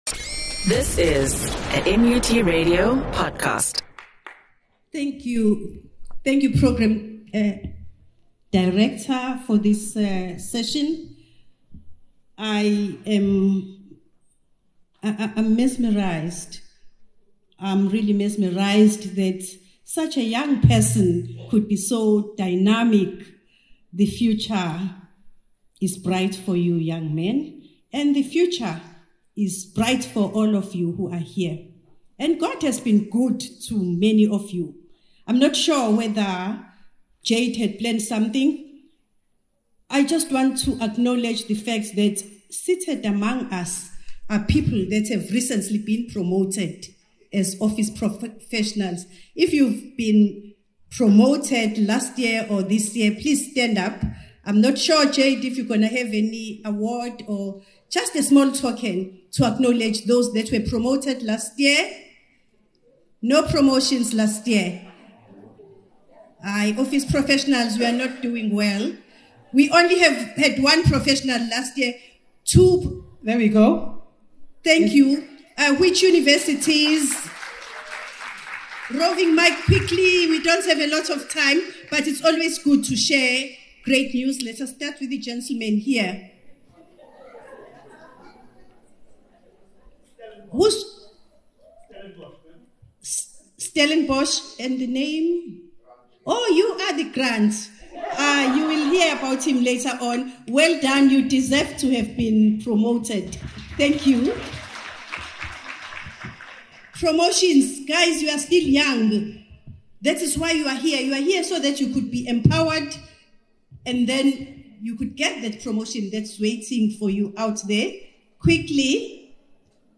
Opening Speech